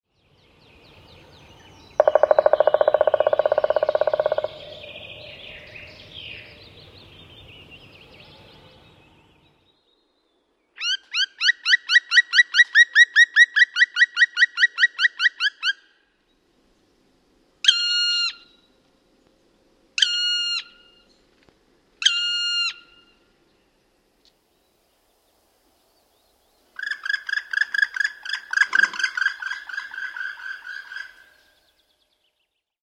Palokärjen sikermä
Palokärki on suurin tikkamme – ja myös kuuluvin.